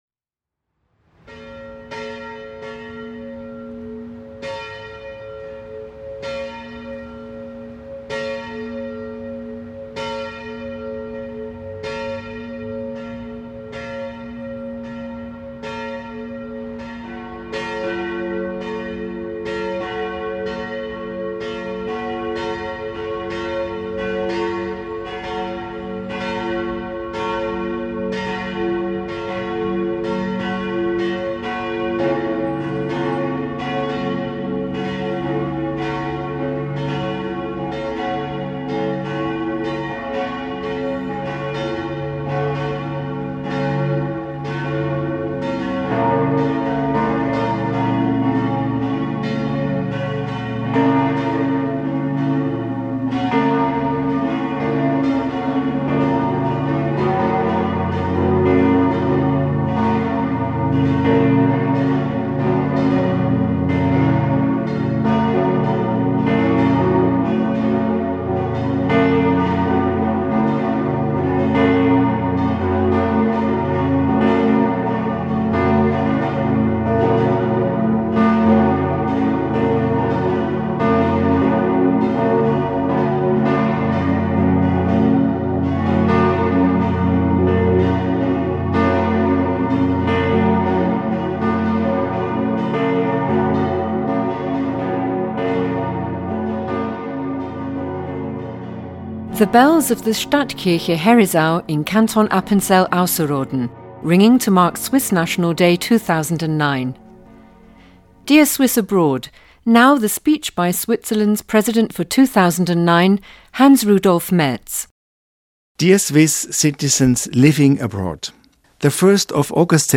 President Hans-Rudolf Merz's speech to the Swiss abroad on the Swiss national day.